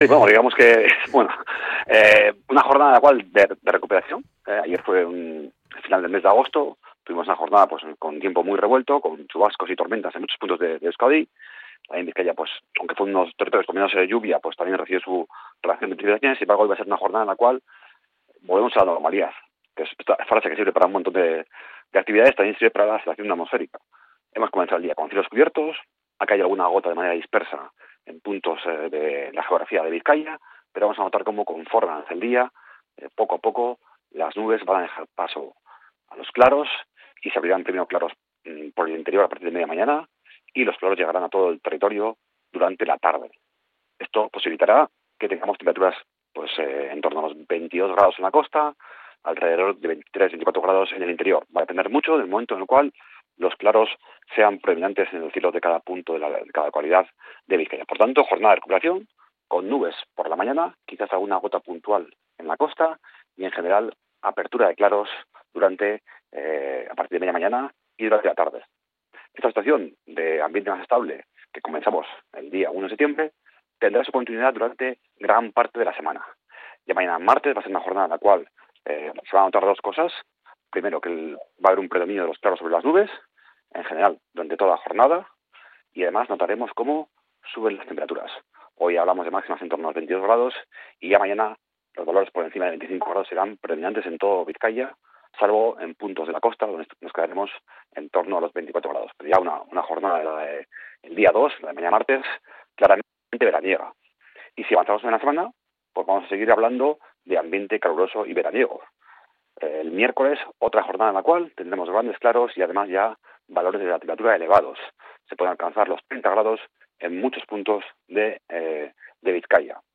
El pronóstico del tiempo en Bizkaia para este 1 de septiembre